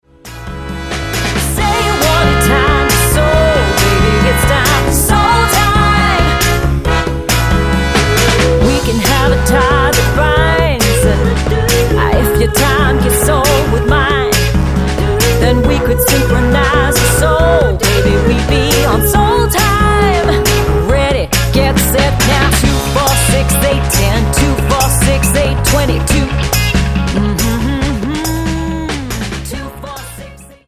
--> MP3 Demo abspielen...
Tonart:Db Multifile (kein Sofortdownload.
Die besten Playbacks Instrumentals und Karaoke Versionen .